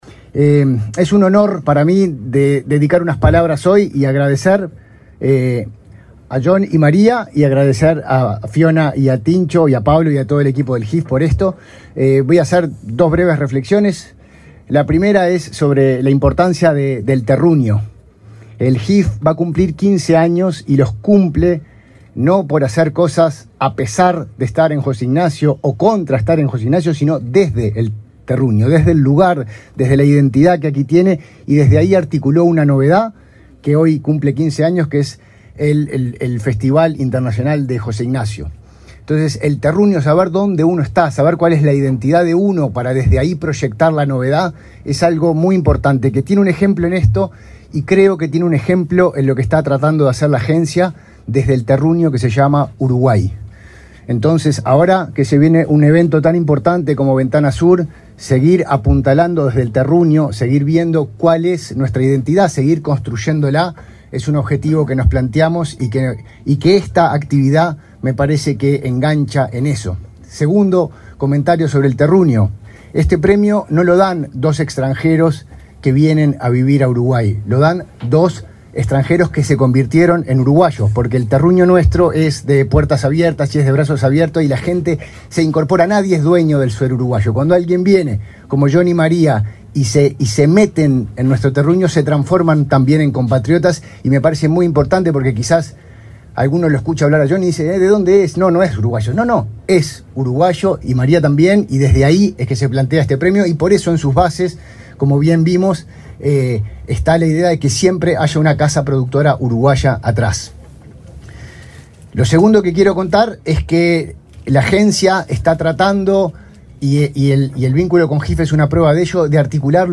El titular de la Agencia del Cine y el Audiovisual del Uruguay (ACAU), Facundo Ponce de León, hizo uso de la palabra, este viernes 19 en Maldonado,